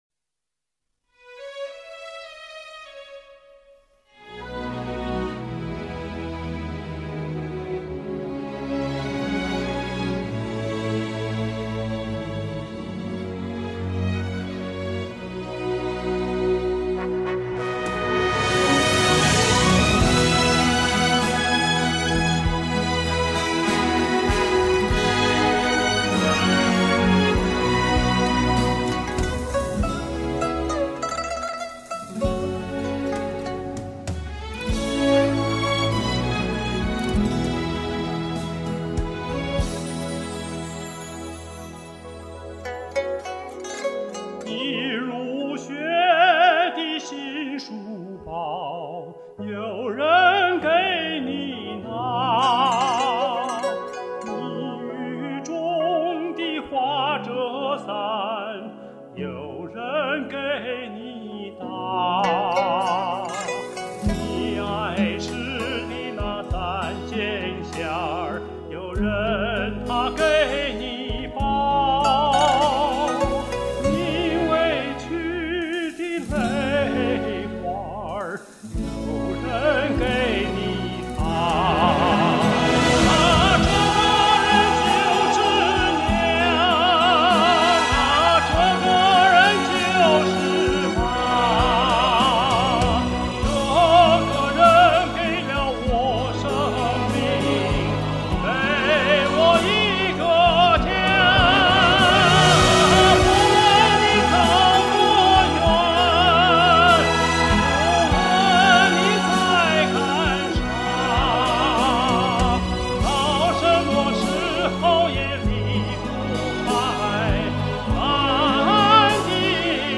唱得深情感人！热烈鼓掌！
悠扬的歌声，感人的演唱，赞！